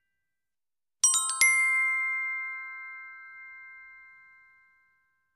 Звуки фэнтези
Звук дива